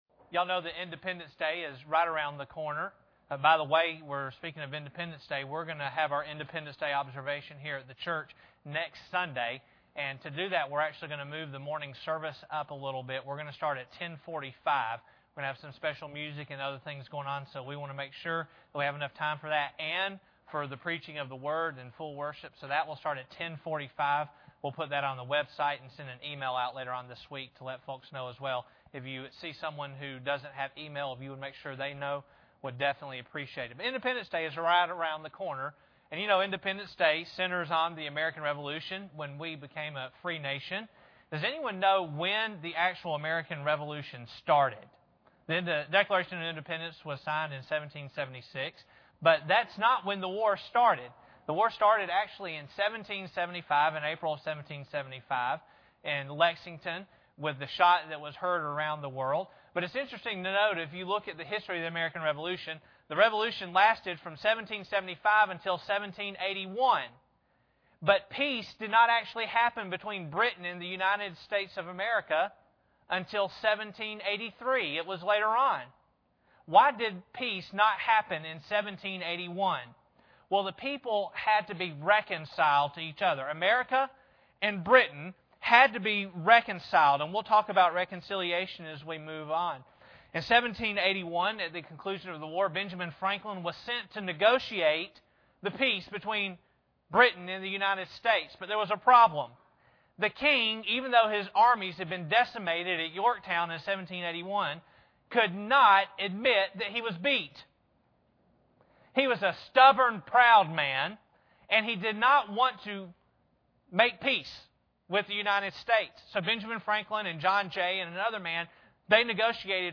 Passage: 2 Corinthians 5:18-21 Service Type: Sunday Morning